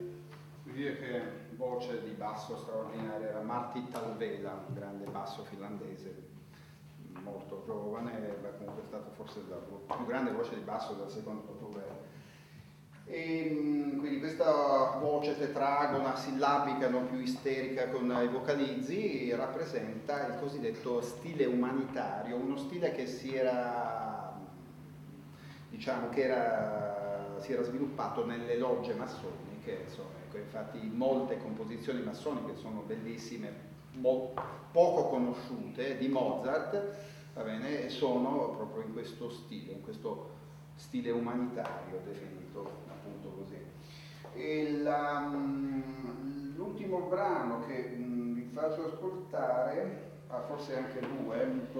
pianoforte
soprano